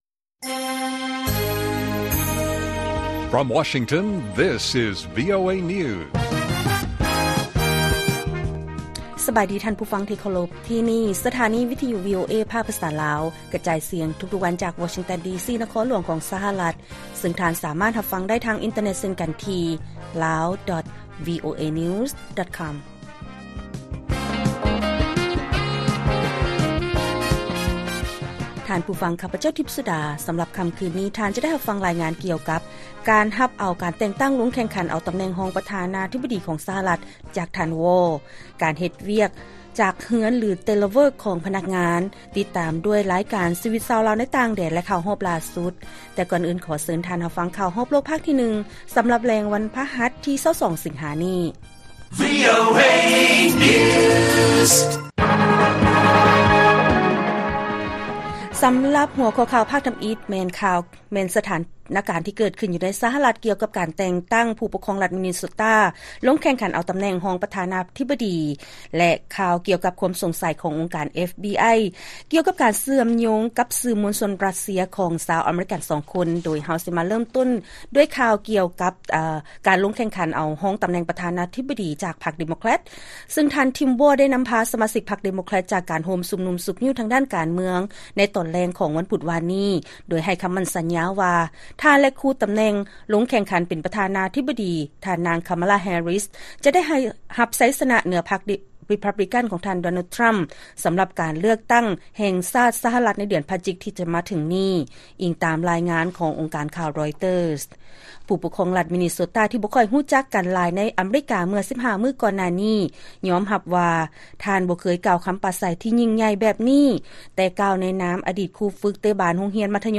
ລາຍການກະຈາຍສຽງຂອງວີໂອເອ ລາວ: ຜູ້ປົກຄອງລັດ ມິນເນໂຊຕາ ຮັບເອົາການແຕ່ງຕັ້ງລົງແຂ່ງຂັນເອົາຕໍາແໜ່ງຮອງປະທານາທິບໍດີຂອງພັກ ເດໂມແຄຣັດ.